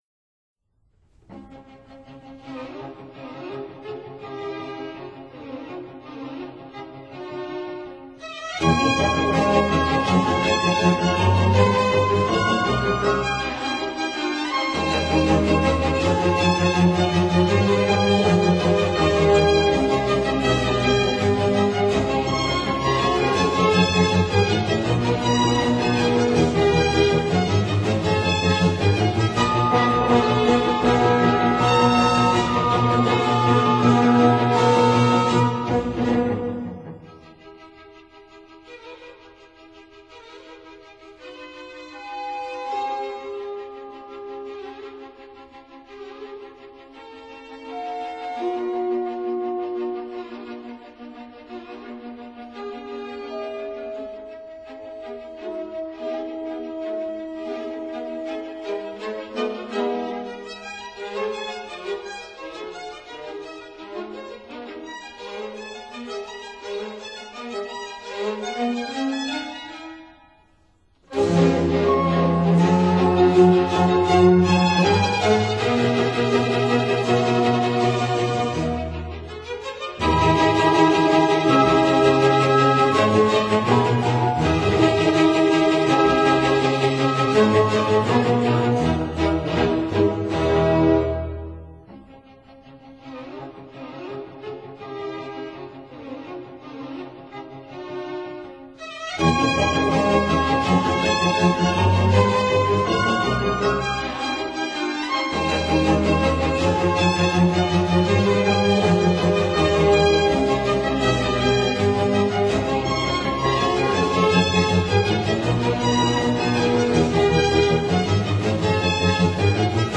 Symphony in C major, VB 138 "Violin obbligato" (1780)
(Period Instruments)